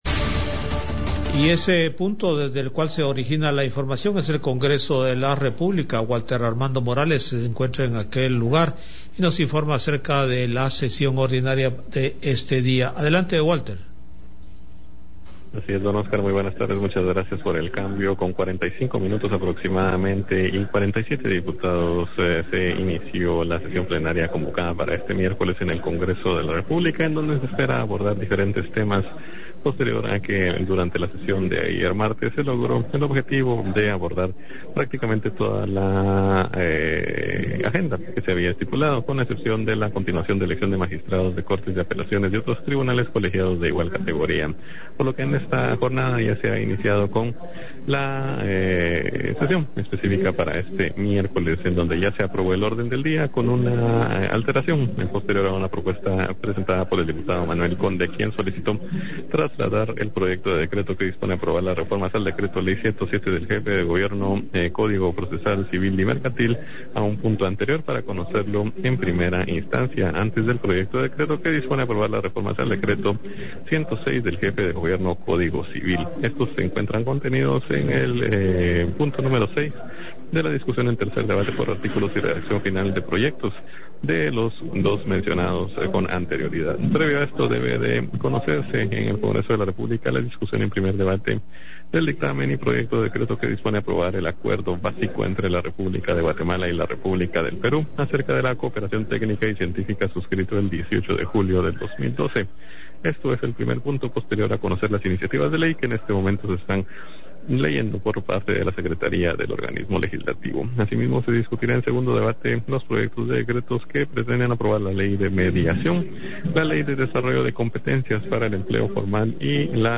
Noticias Iniciativa de Ley 5157